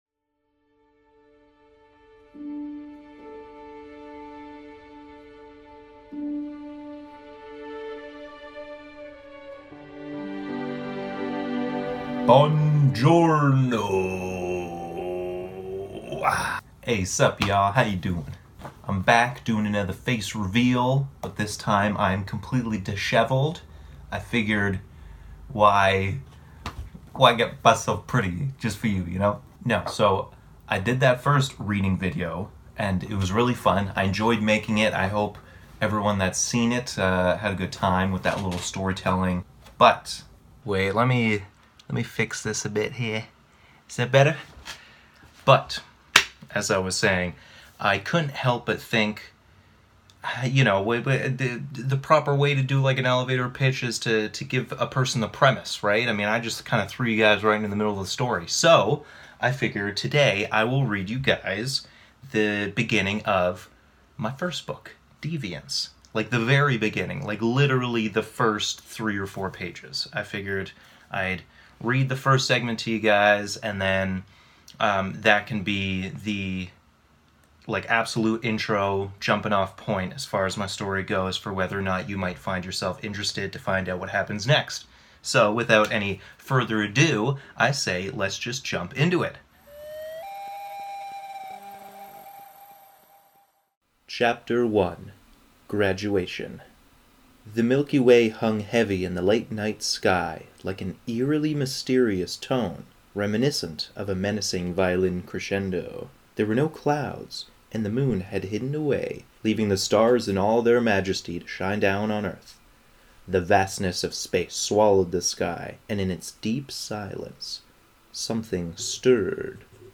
Another Reading
Here's another video of me reading a sample of my writing. This time it's the very beginning of my first book: Deviance.